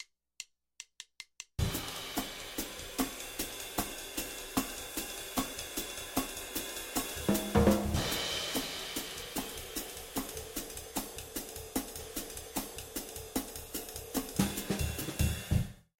Jazz_Drums.mp3